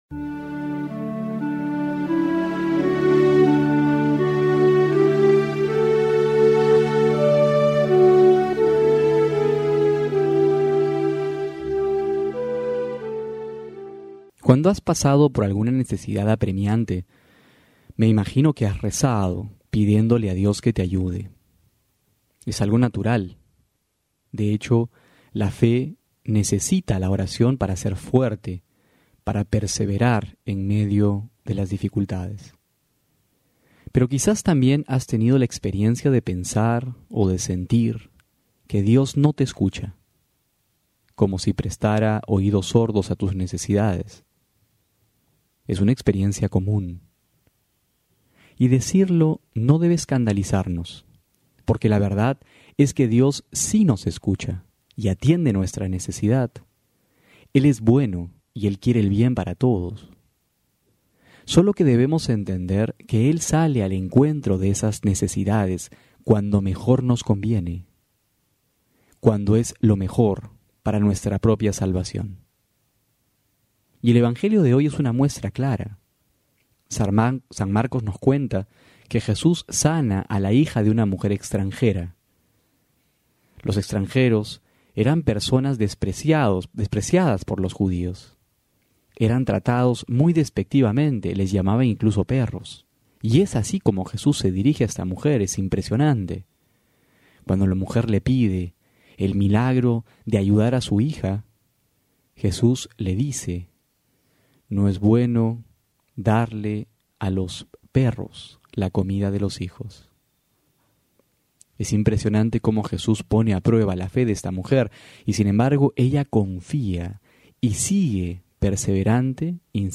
Homilía para hoy: Marcos 7,24-30
febrero09-12homilia.mp3